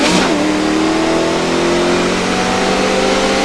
brute_secondgear.wav